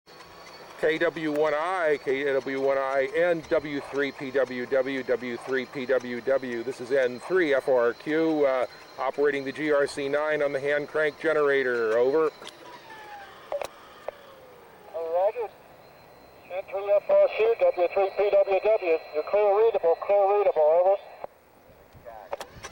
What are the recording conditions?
Radio squads were deployed to the summit of Big Pocono Mountain for this year's field exercise. The following recording is ambient audio.